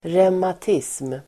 Ladda ner uttalet
Uttal: [revmat'is:m]